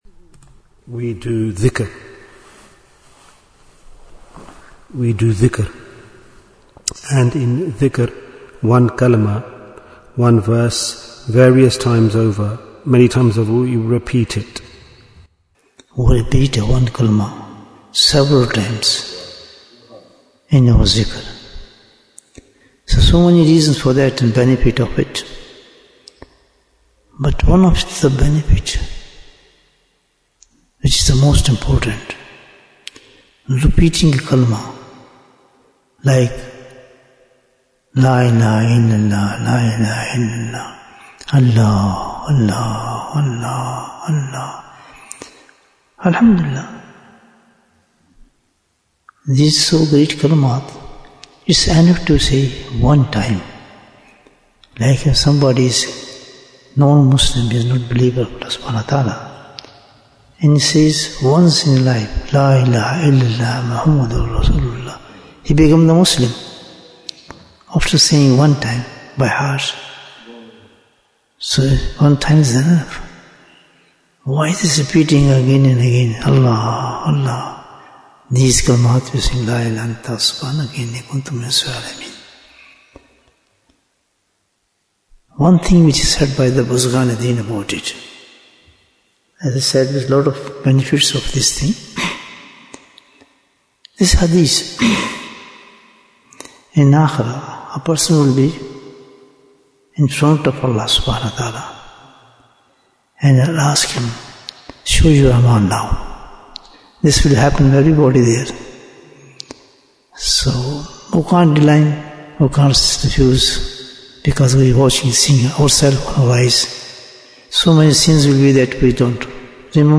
Jewels of Ramadhan 2026 - Episode 26 Bayan, 21 minutes9th March, 2026